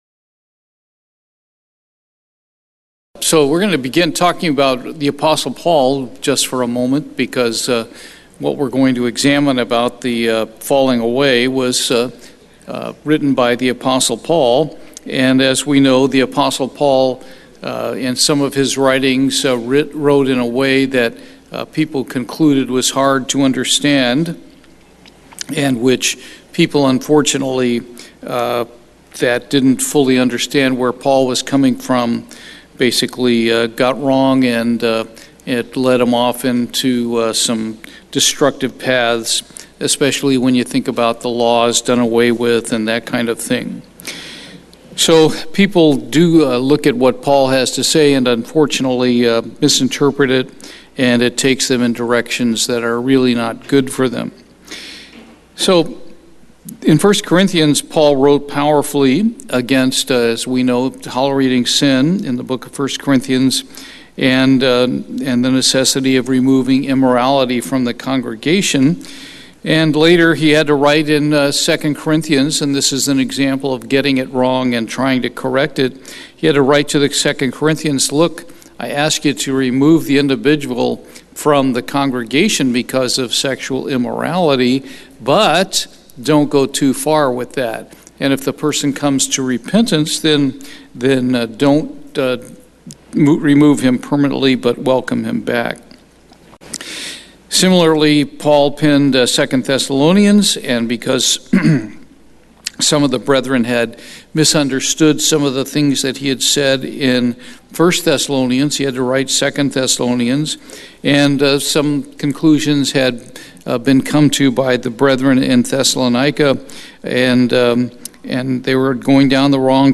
Bible Study, The Falling Away